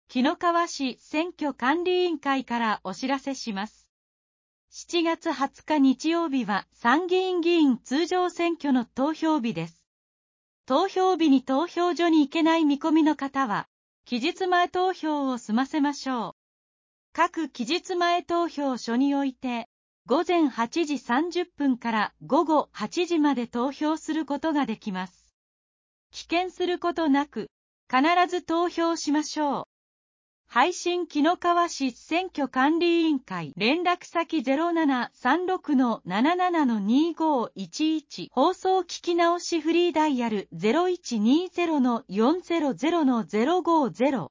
防災行政無線情報 | 和歌山県紀の川市メール配信サービス